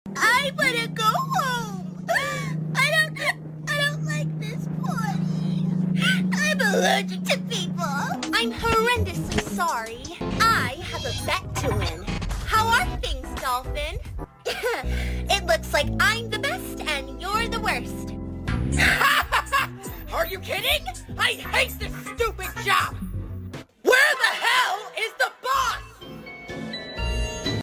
I’ve been wanting to try Ena’s voice out, for forever!! How do you think I did?⚡ Voice Filter (CapCut): I used the ‘Retro-Vibe’ voice filter on CapCut to get the grainy effect!! I screen-recorded, so I didn’t need to worry about saving it!!